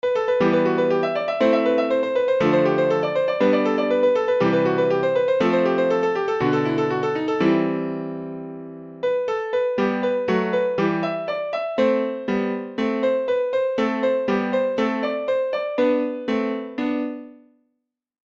Придумал на гитаре пассаж. Решил немного переделать. Вбил во фрукт. Получилось что-то классическое. Задумывалось под клавесин, но что-то я не могу найти нормального VST :gg:
Диссонанс какой-то проявляется.
Classical.mp3